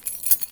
R - Foley 13.wav